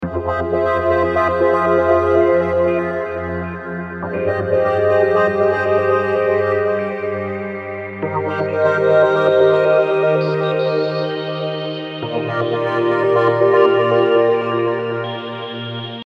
Calming Ambient Analog Synth Loop for Projects
Description: Calming ambient analog synth loop 120 BPM.
Genres: Synth Loops
Tempo: 120 bpm
Calming-ambient-analog-synth-loop-120-BPM.mp3